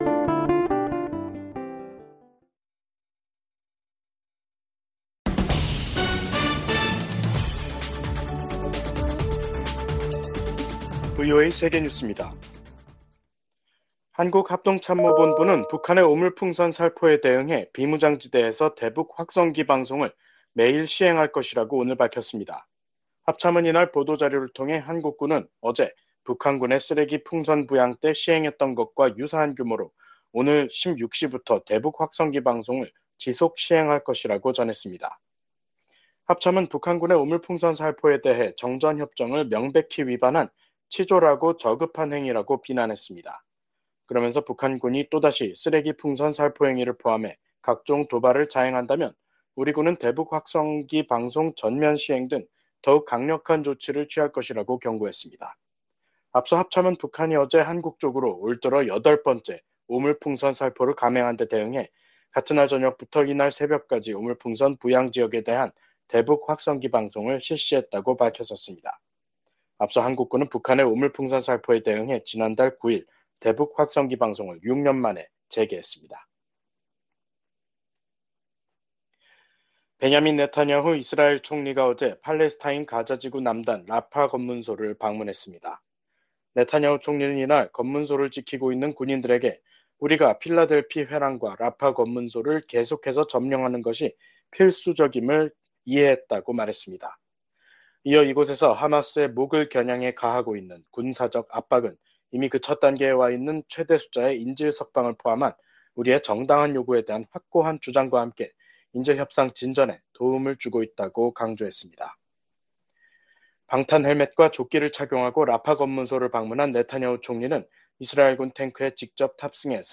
VOA 한국어 간판 뉴스 프로그램 '뉴스 투데이', 2024년 7월 19일 2부 방송입니다. 도널드 트럼프 전 대통령이 공화당 대선 후보 수락 연설에서 미국 사회의 불화와 분열이 빠르게 치유돼야 한다고 강조했습니다. 미한일 합참의장이 3국 다영역 훈련인 프리덤 에지 훈련을 확대하기로 합의했습니다. 북한 군인들이 국제법에 반하는 심각한 강제노동에 시달리고 있다는 우려가 국제사회에서 제기되고 있습니다.